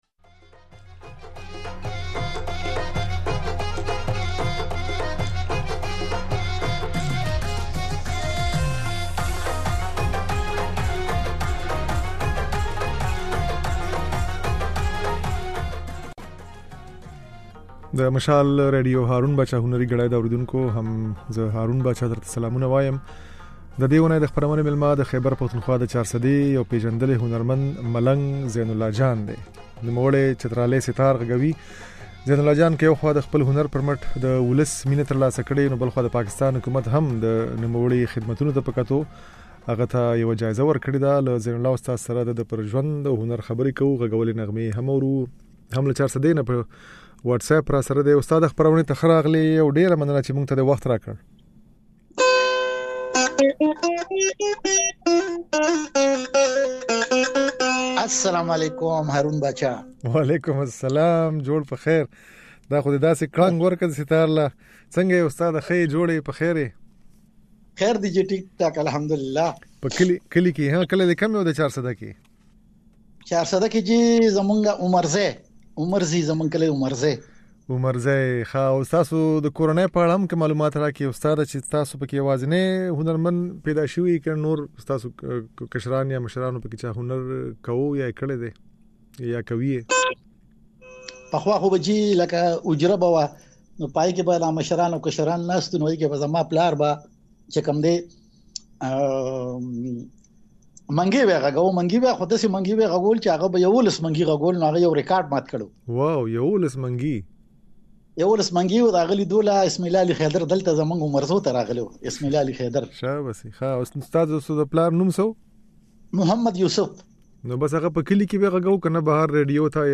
د چترالي سيتار ځينې نغمې يې په خپرونه کې اورېدای شئ.